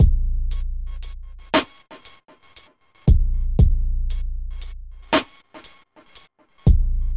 进取的钢琴鼓部分BPM 117调B大调
Tag: 117 bpm Hip Hop Loops Drum Loops 1.21 MB wav Key : B Studio One